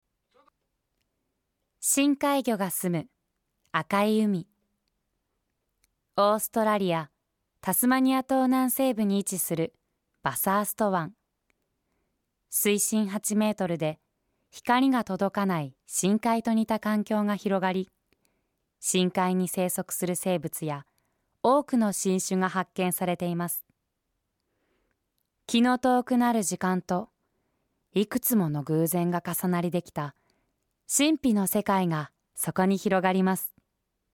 ボイスサンプル